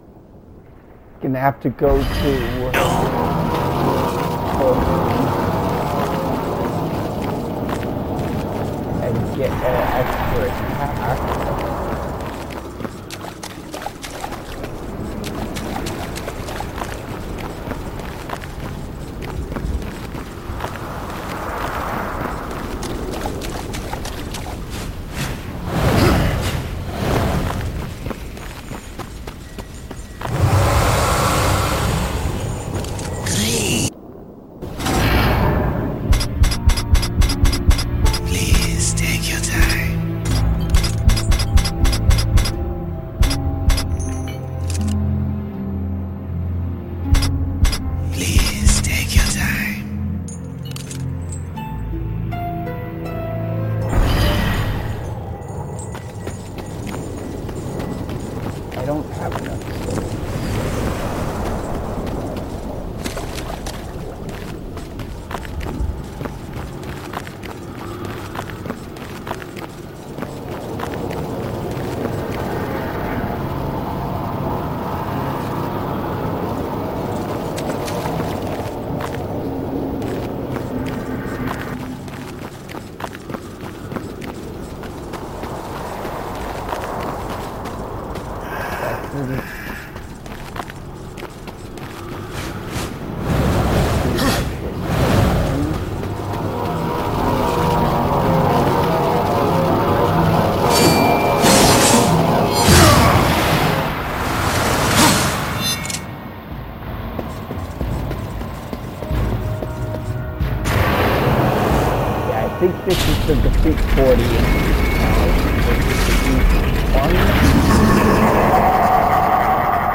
I play Darksiders with commentary